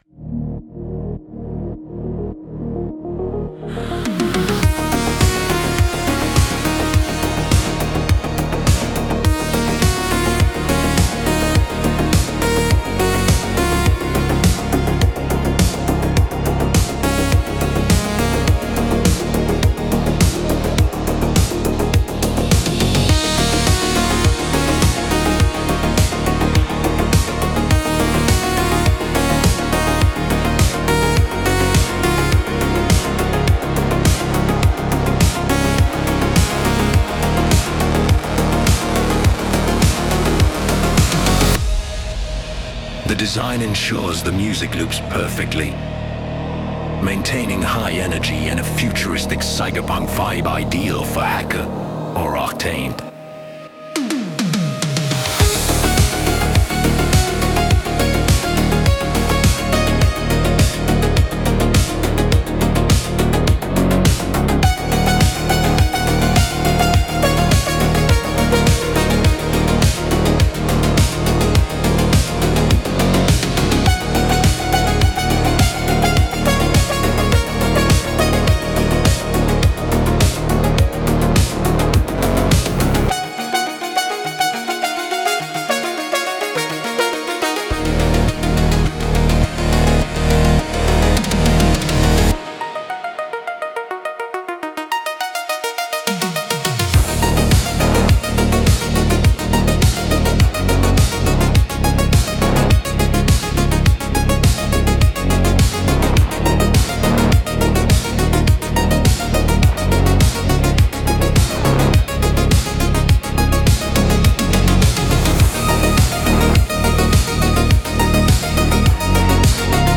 synthwave soundtrack
Genre: Synthwave / Cyberpunk / Retro Electronic